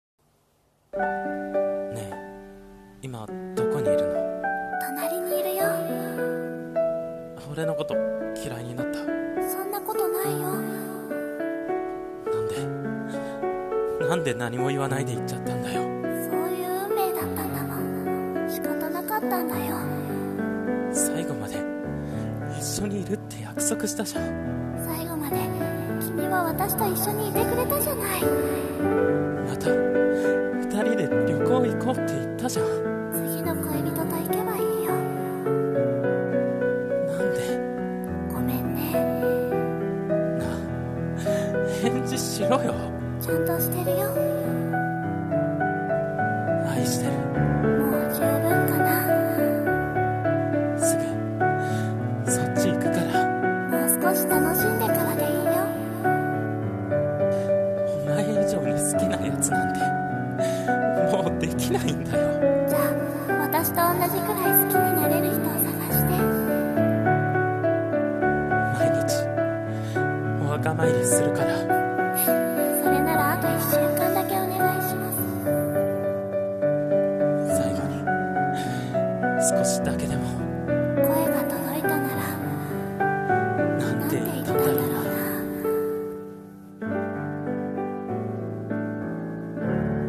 最後の最後に 声劇